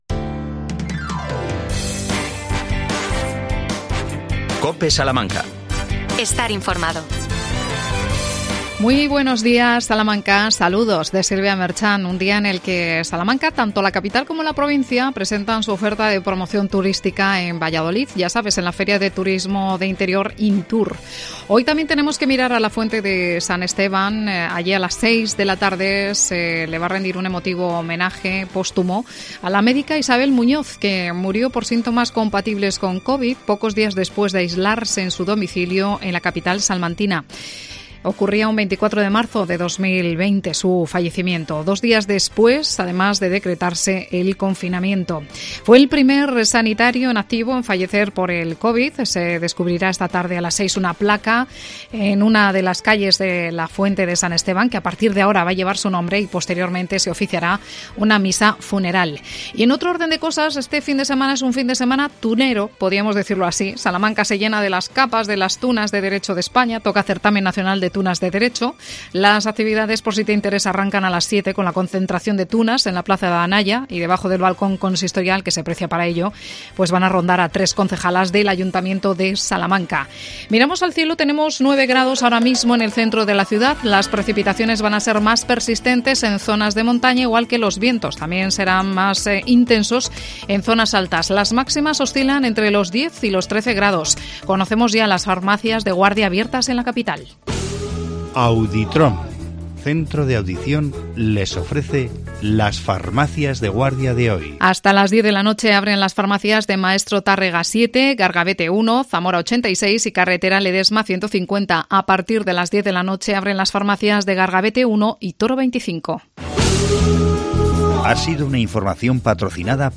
AUDIO: III Salón del Libro Infantil y Juvenil. Entrevistamos